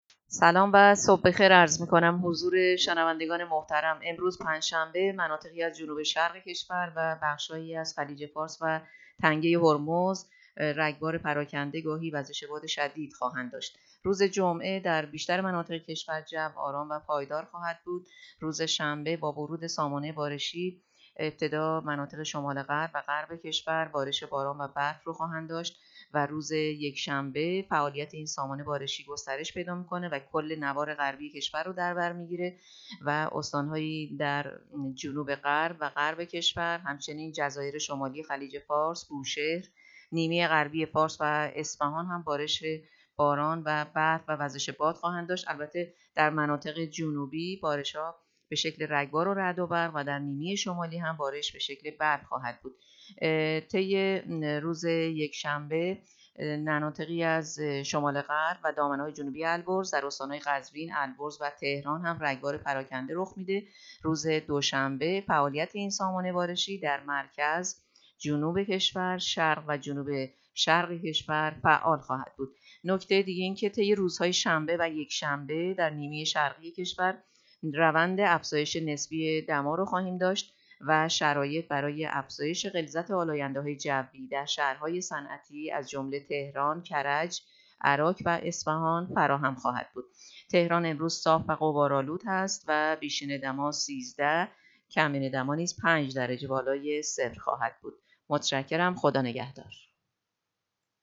گزارش رادیو اینترنتی پایگاه‌ خبری از آخرین وضعیت آب‌وهوای ۲۰ دی؛